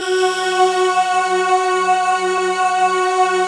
Index of /90_sSampleCDs/Optical Media International - Sonic Images Library/SI1_Breath Choir/SI1_BreathMellow